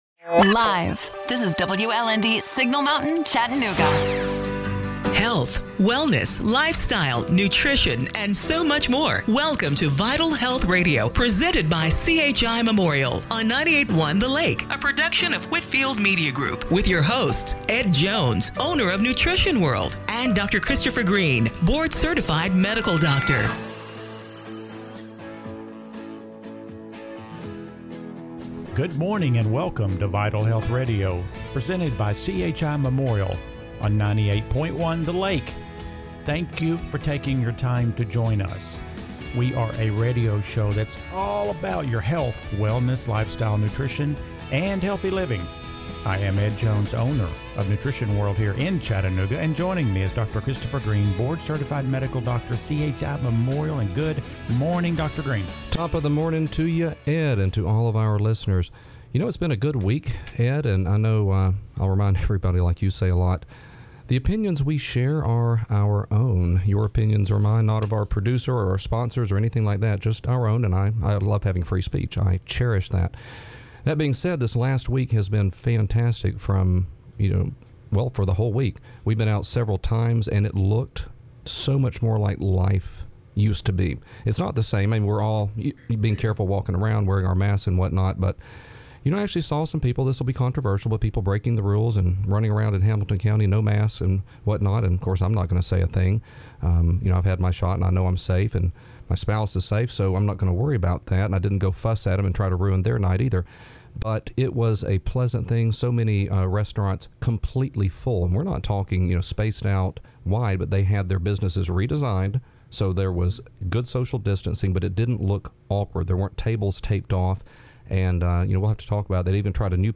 March 21, 2021 – Radio Show - Vital Health Radio